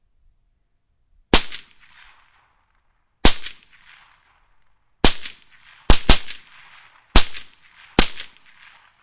描述：在美国俄勒冈州的美国内战重演中录制，2012年。黑火药步枪，大炮，几乎是一场完整的战斗。大量的范围。鼓队、管子、哭声、骑兵冲锋。用Tascam DR08录制了离行动现场大约一个足球的长度。
标签： 战争 步枪 内战 现场记录 骑兵
声道立体声